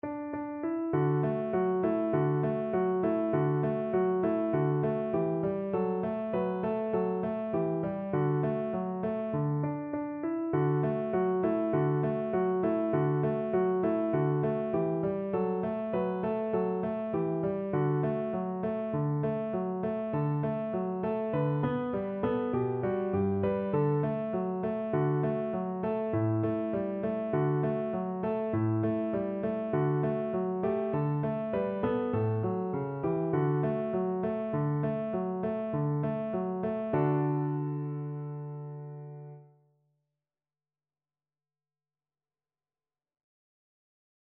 Es ist fur uns eine Zeit angekommen Free Sheet music for Piano
es_ist_fur_uns_eine_zeit_PNO.mp3